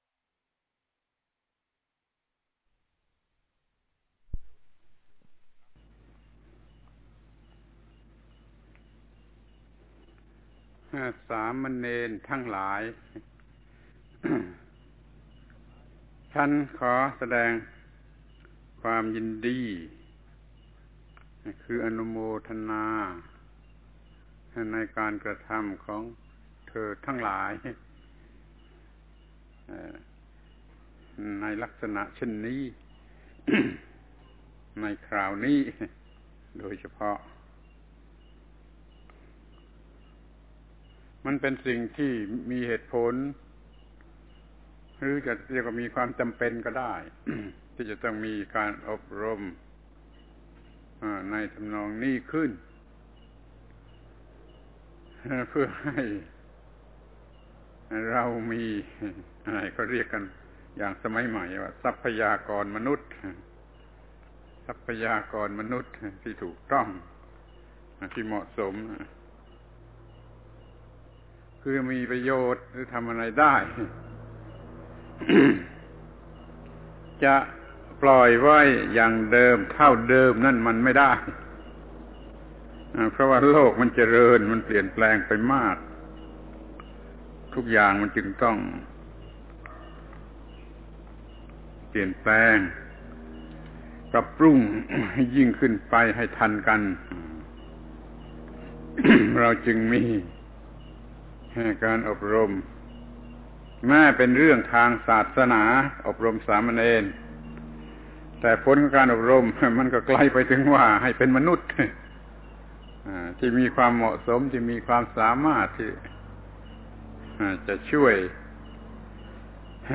ฟังธรรมะ Podcasts กับ พระธรรมโกศาจารย์ (พุทธทาสภิกขุ)